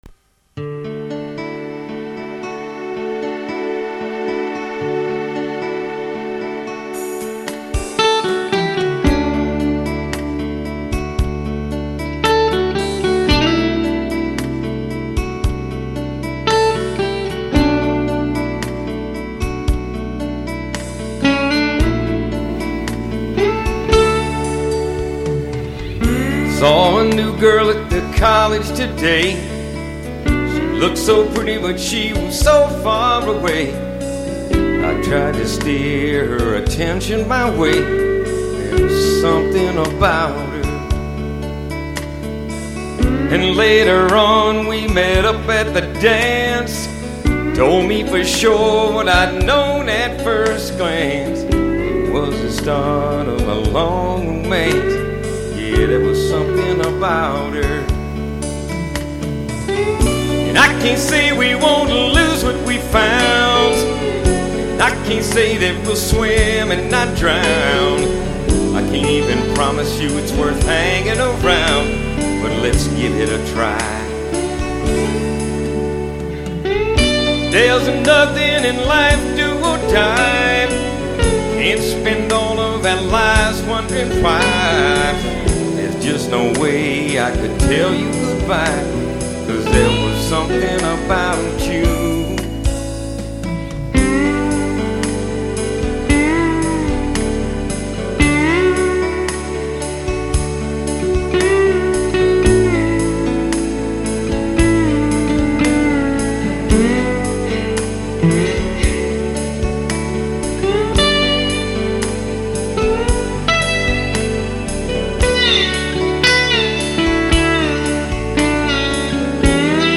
Heute dachte ich dann, eigentlich müßte da doch ne Slidegitarre gut zu passen, schade nur, daß ich immer noch nicht sliden kann.
Das Ganze war dann ziemlich frustrierend, zuerst nahm ich meine PhilGOOD, das war vom Sound her schon prima aber grausam gespielt, dann dachte ich, ich könnte mich durch mehr Gain retten und kramte die Danelectro aus der Ecke.
Ich habe mir dann nochmal die PhilGOOD gegriffen und einen letzten Versuch gestartet und da ich nicht viele Noten gespielt habe, kann ich nun für ein kleines Weilchen damit leben.